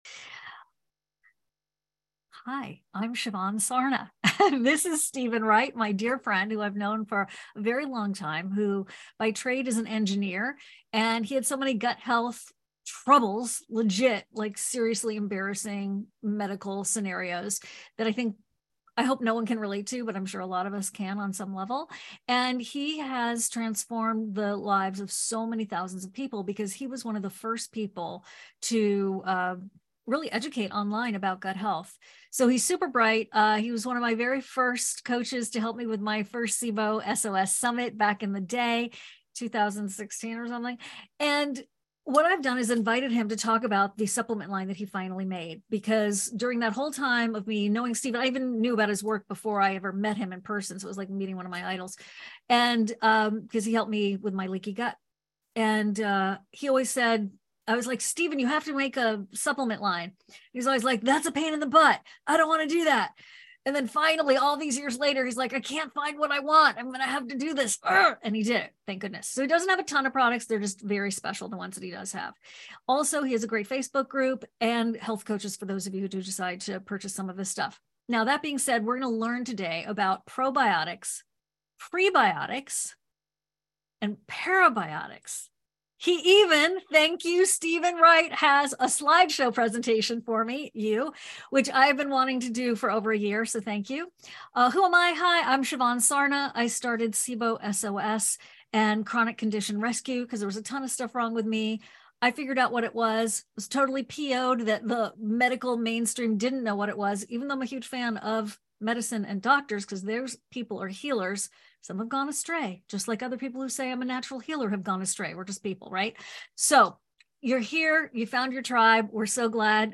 Probiotics, Prebiotics, and Paraprobiotics: A 101 Lesson